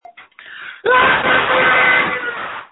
Scream #06993 0:57 UTC 3s 🔗
• When you call, we record you making sounds. Hopefully screaming.